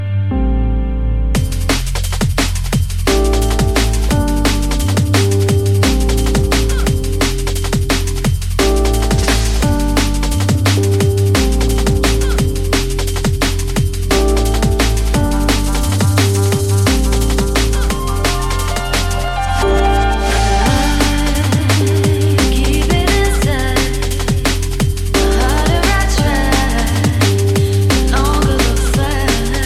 TOP >Vinyl >Drum & Bass / Jungle
TOP > Vocal Track
TOP > Deep / Liquid